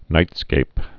(nītskāp)